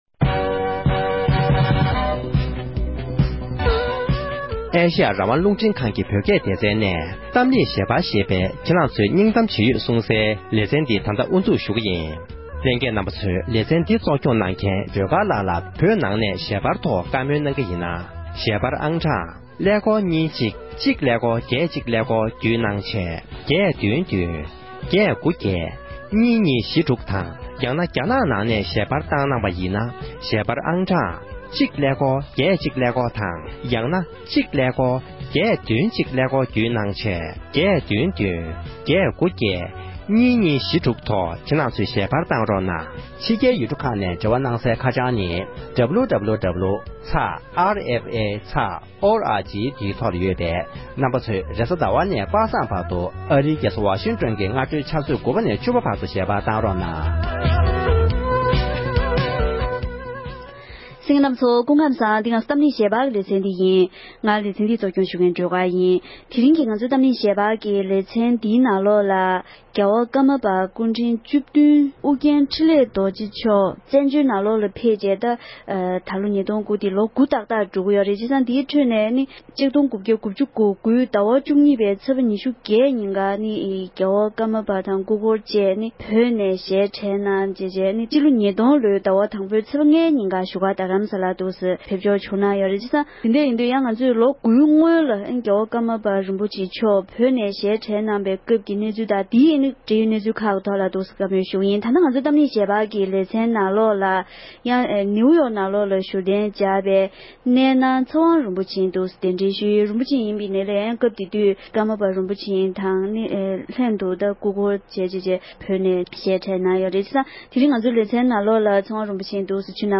ཀརྨ་པ་རིན་པོ་ཆེའི་ལྷན་དུ་བཙན་བྱོལ་དུ་ཕེབས་མཁན་སྐུ་འཁོར་ཞིག་གི་ལྷན་དུ་བཀའ་མོལ་ཞུས་པ།